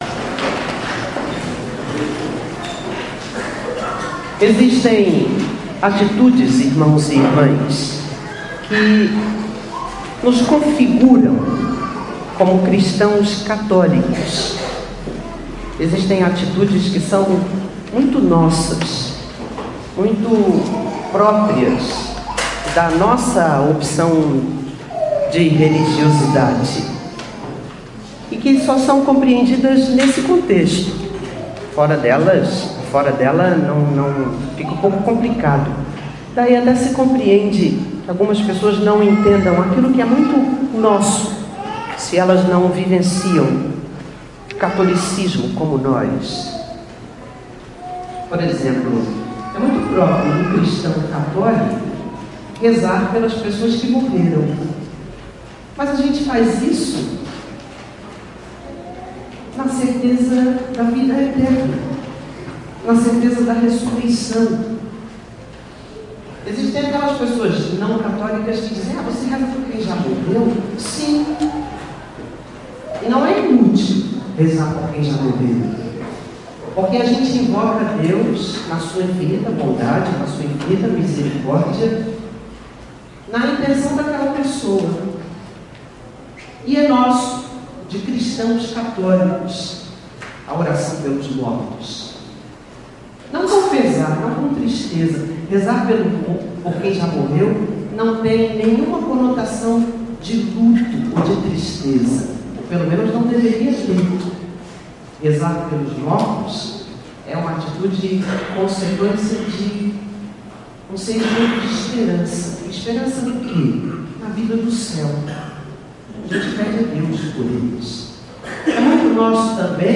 Homilia
Homilia-do-29º-Domingo-do-Tempo-Comum.mp3